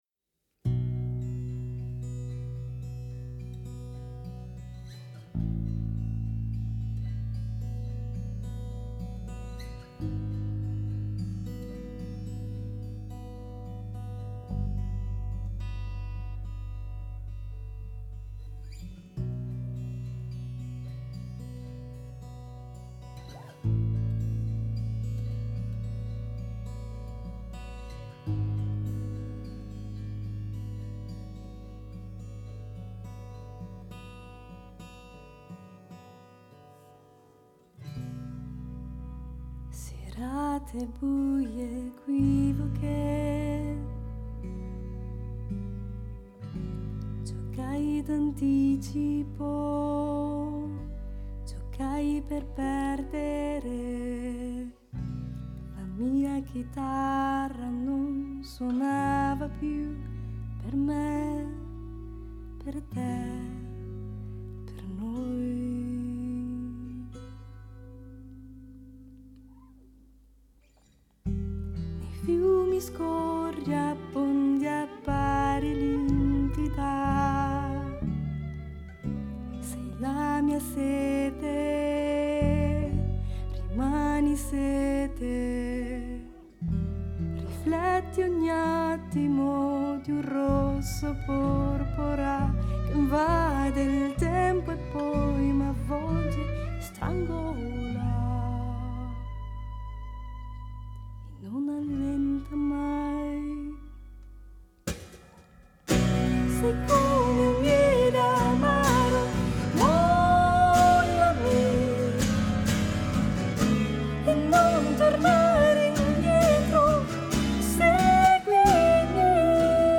violino